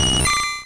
Cri de Poissirène dans Pokémon Rouge et Bleu.